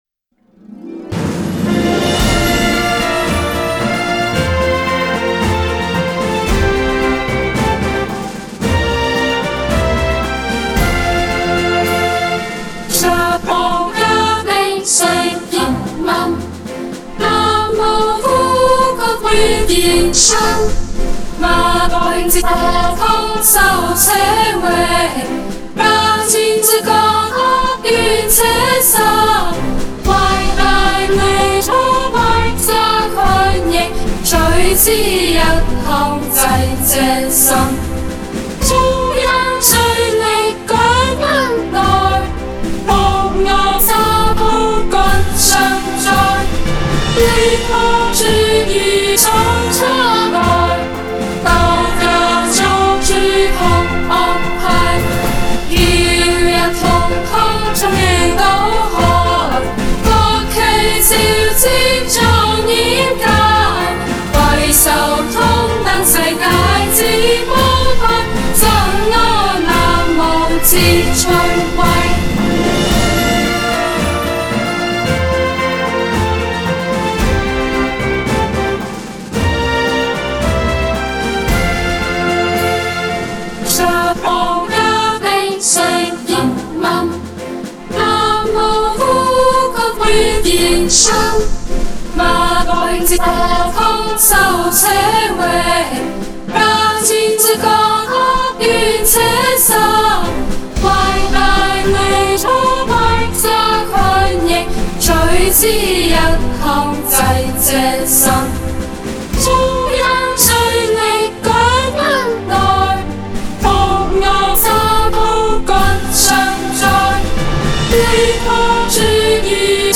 首演演唱爱国青年集体合唱
其旋律线条简洁明快，节奏铿锵有力，易于记忆和传唱，符合革命歌曲和国歌广泛传播的需求。编曲上，官方版本常采用管弦乐团伴奏，营造宏大辉煌的气势，以表现国家的威严和人民的自豪感。整体音乐情绪积极向上，充满乐观主义和革命豪情，旨在激发听众的爱国热情和对党、对领袖、对国家制度的忠诚。